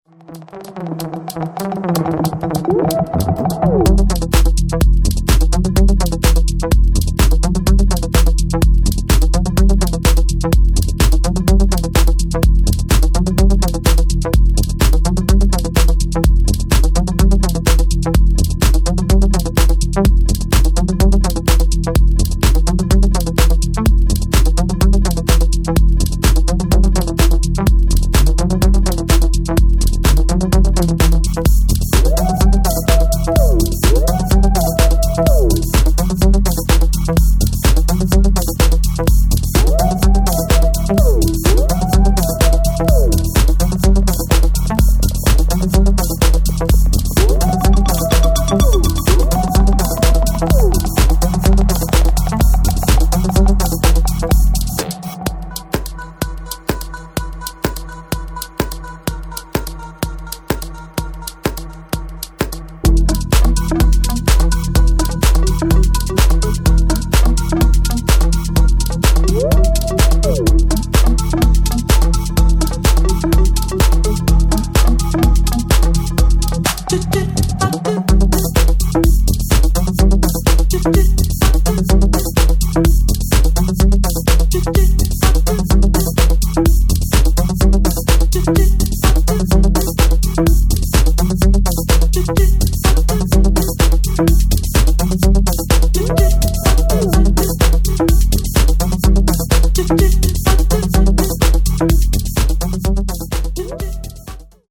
[ TECHNO | HOUSE ]